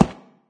grass.ogg